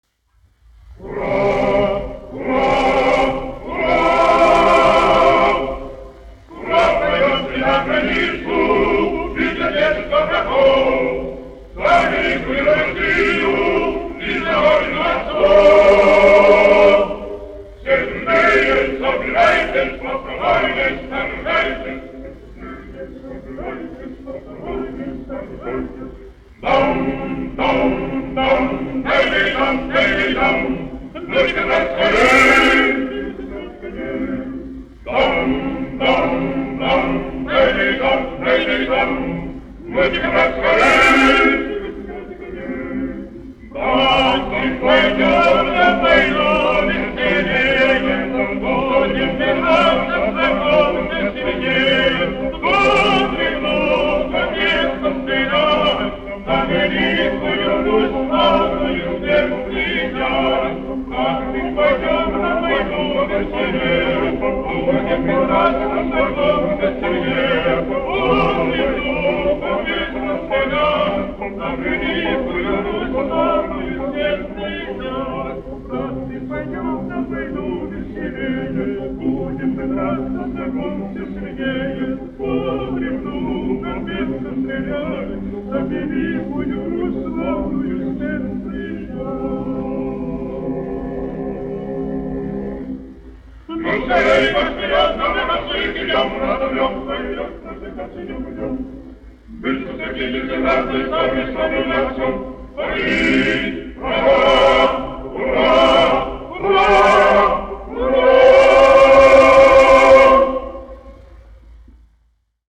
1 skpl. : analogs, 78 apgr/min, mono ; 25 cm
Marši
Kori (vīru)
Latvijas vēsturiskie šellaka skaņuplašu ieraksti (Kolekcija)